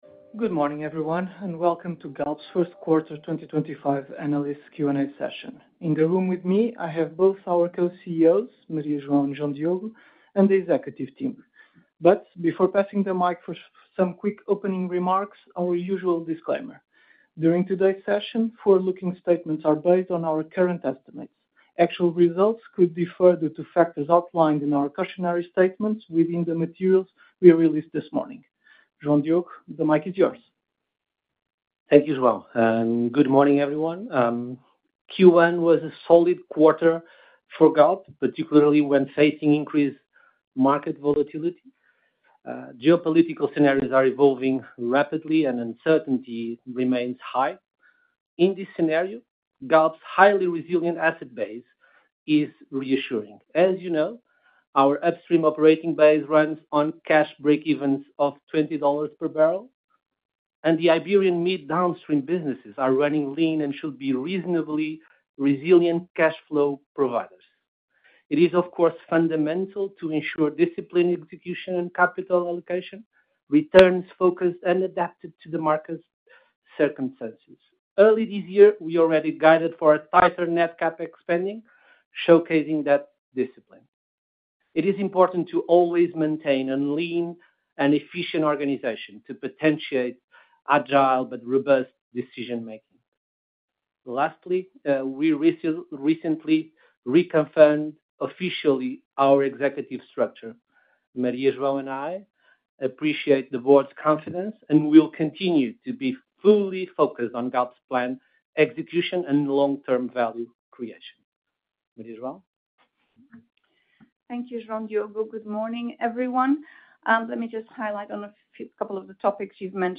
Ouvir a conference call - 1Q - 2023